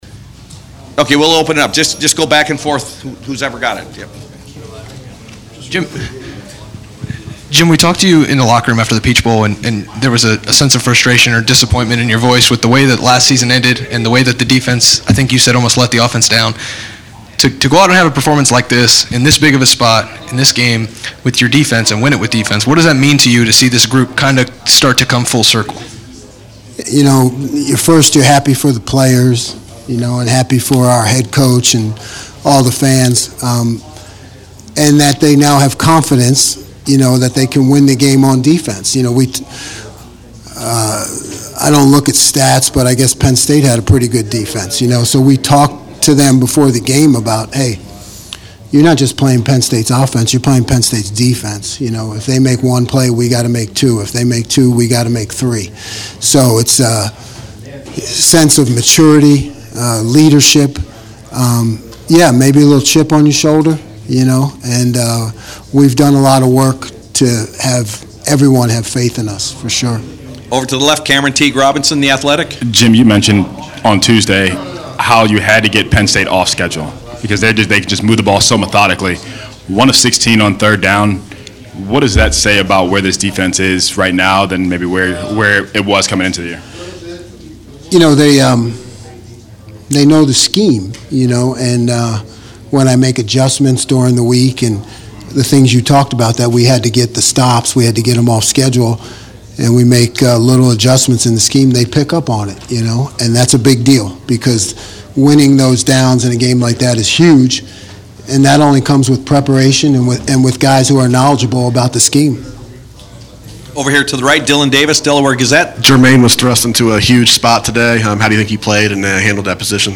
Postgame Press Conference